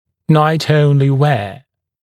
[naɪt-‘əunlɪ weə][найт-‘оунли уэа]ношение только ночью, в ночное время